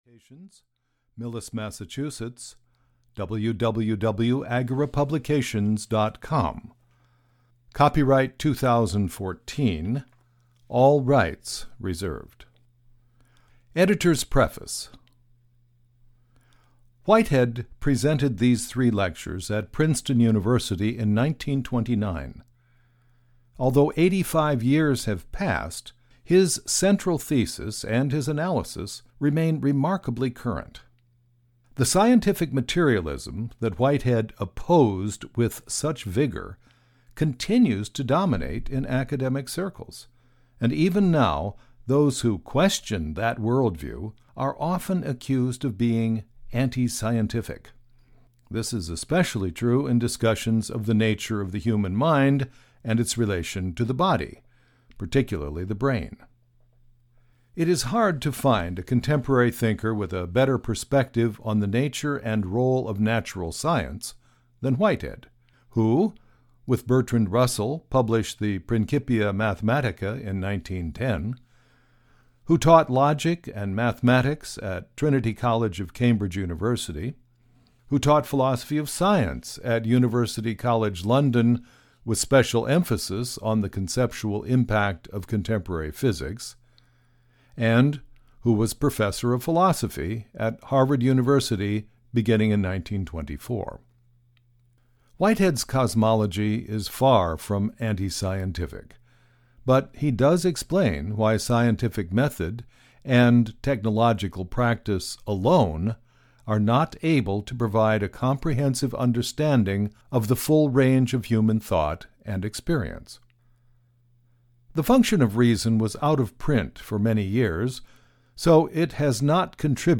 Audio knihaWhitehead’s The Function of Reason (EN)
Ukázka z knihy